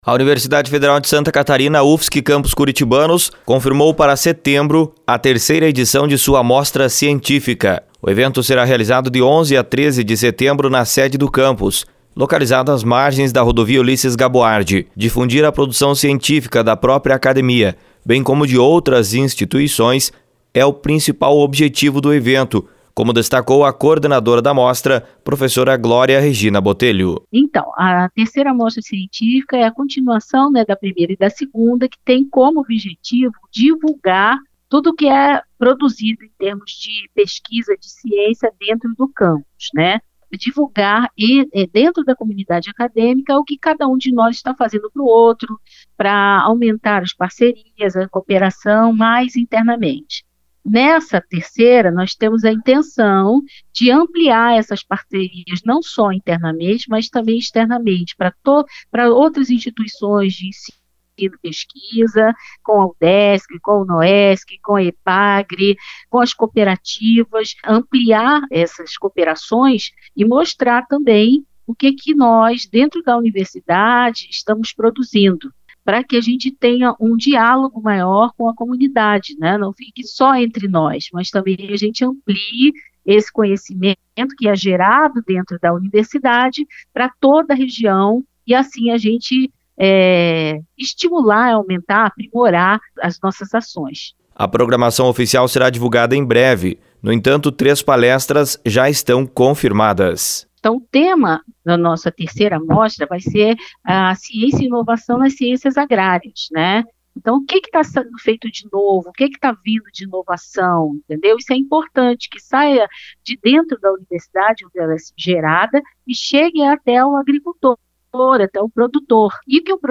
Informações com repórter